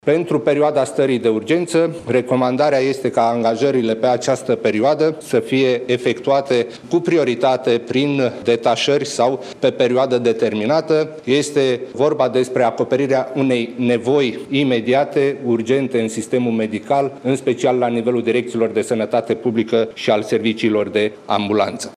Purtătorul de cuvânt al Guvernului, Ionel Dancă: